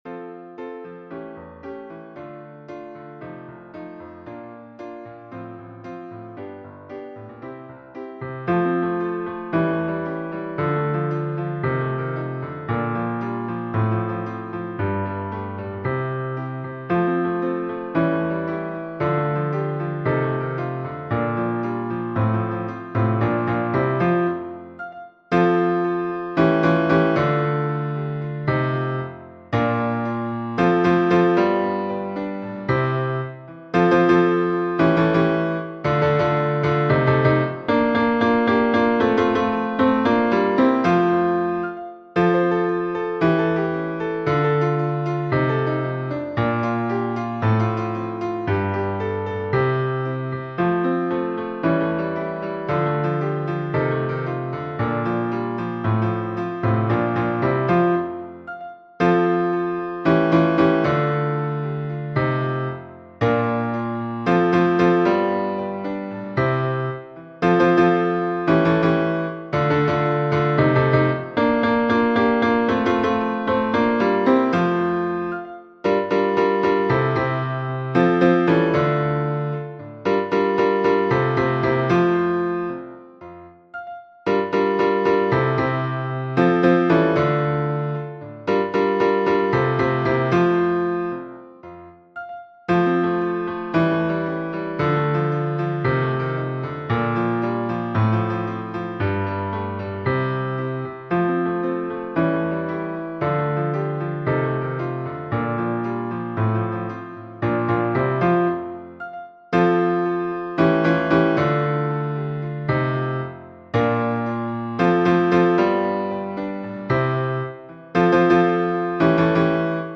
MP3 version piano
Basse